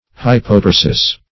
Search Result for " hypotarsus" : The Collaborative International Dictionary of English v.0.48: Hypotarsus \Hy`po*tar"sus\, n.; pl.